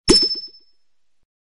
PlaceGemInGate.ogg